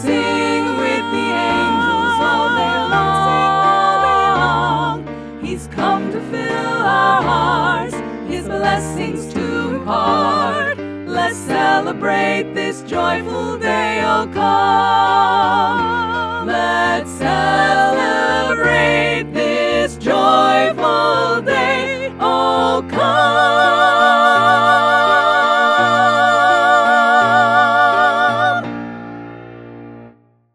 choral song arrangements